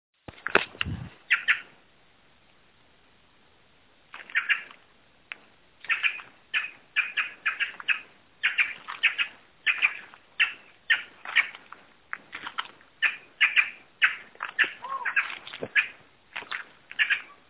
Plush-crested Jay (Cyanocorax chrysops)
Varios ejemplares se movian y vocalizaban juntos en la selva, se observo a una urraca alimentarse en la corteza de un arbol a baja altura
Location or protected area: Parque Provincial Cruce Caballero
Certainty: Observed, Recorded vocal
URRACAS---Misiones-PP-Cruce-Caballero.mp3